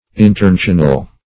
Search Result for " internecinal" : The Collaborative International Dictionary of English v.0.48: Interneciary \In`ter*ne"cia*ry\, Internecinal \In`ter*ne"ci*nal\, a. Internecine.